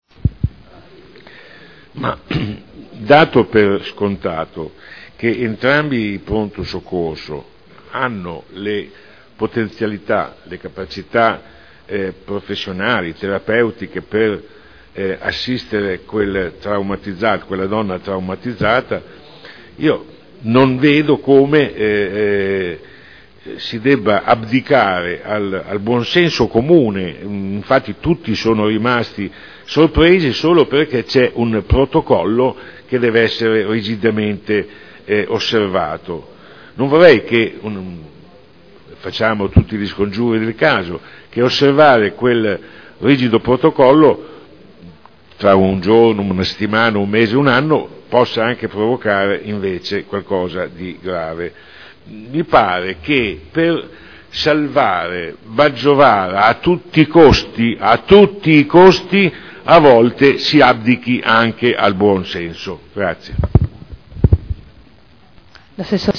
Dibattito.